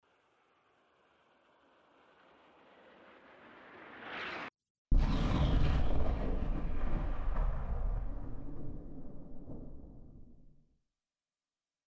mortarimpactflame100m.mp3